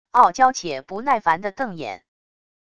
傲娇且不耐烦的瞪眼wav音频